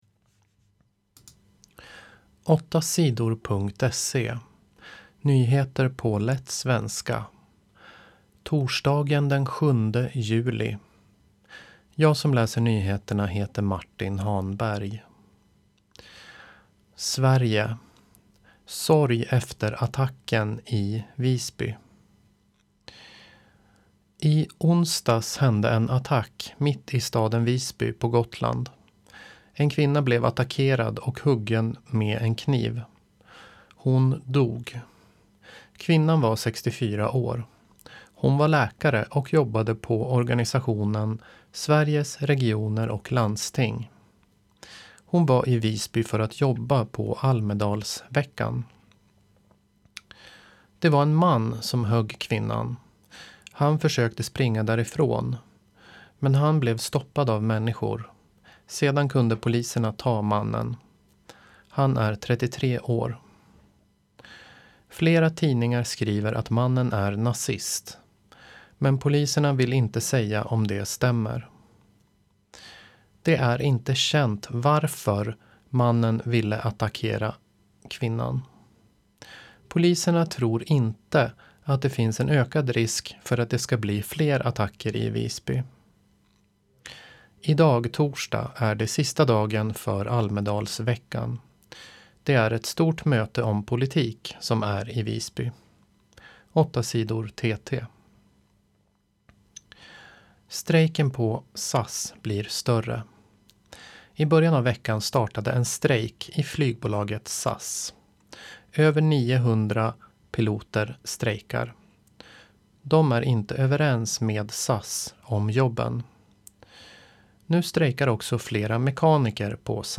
Nyheter på lätt svenska den 7 juli